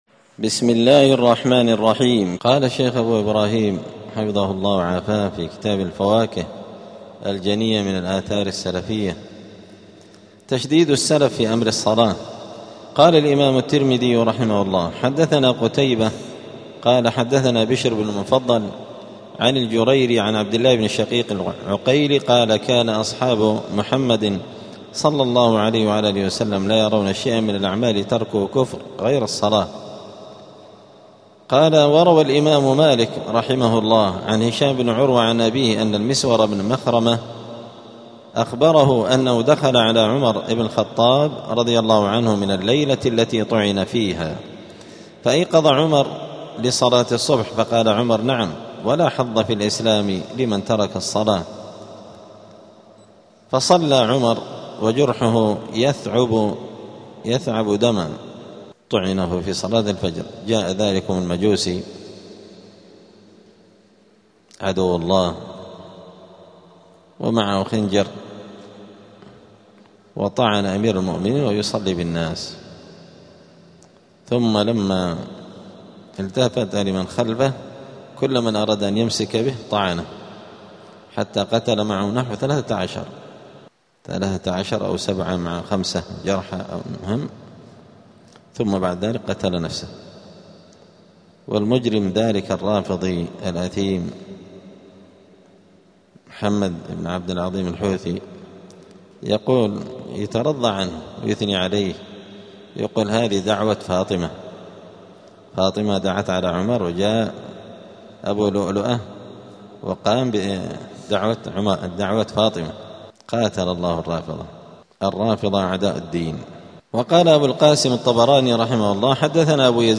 دار الحديث السلفية بمسجد الفرقان بقشن المهرة اليمن
*الدرس الثاني والتسعون والأخير (92) {تشديد السلف في أمر الصلاة}.*